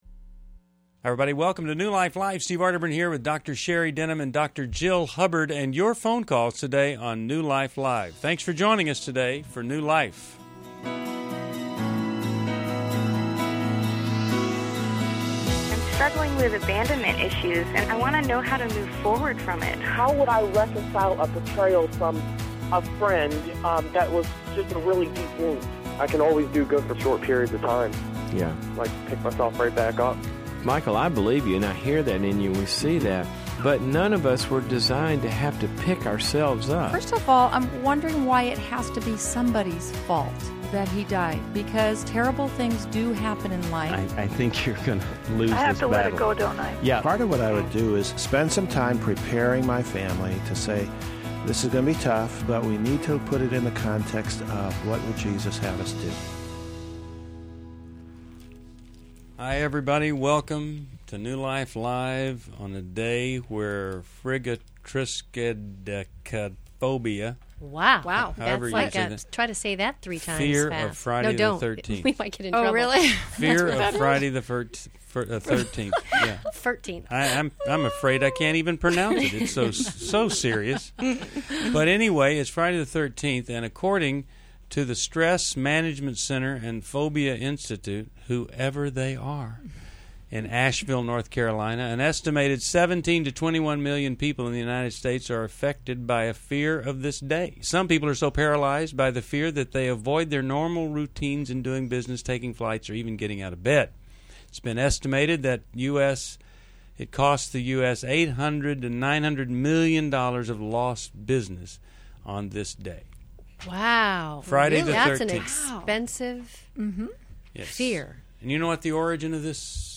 Caller Questions: 1.